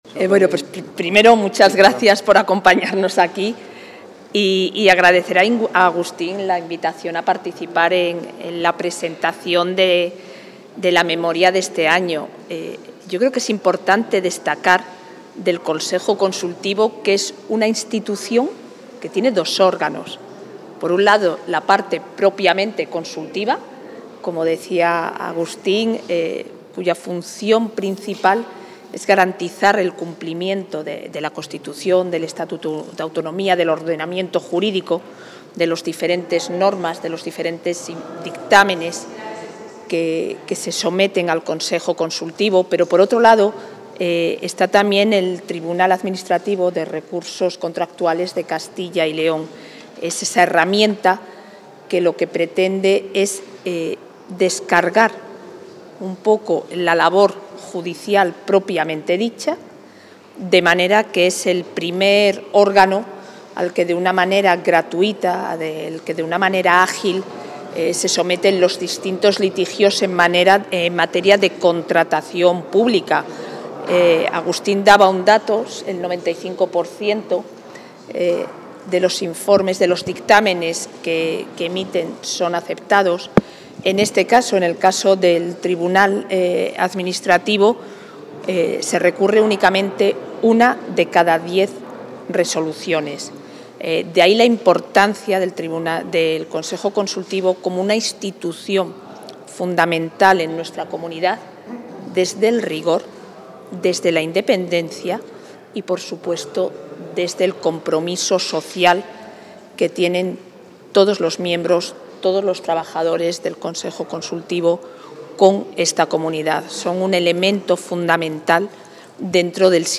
Intervención de la vicepresidenta.
La vicepresidenta de la Junta de Castilla y León y consejera de Familia e Igualdad de Oportunidades, Isabel Blanco, ha asistido hoy a la sesión plenaria de aprobación de la Memoria 2024 del Consejo Consultivo y del Tribunal Administrativo de Recursos Contractuales.